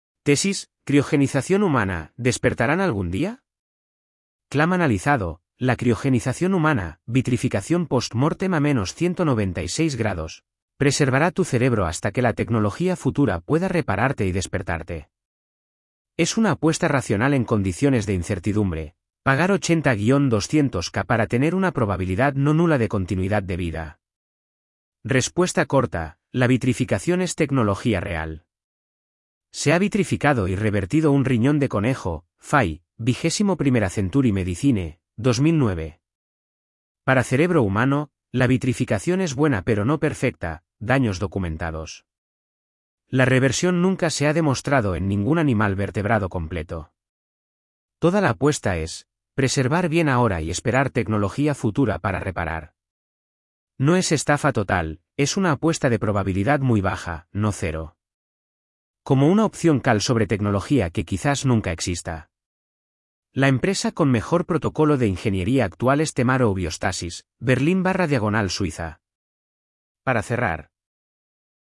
Voz: Microsoft Alvaro (es-ES, neural).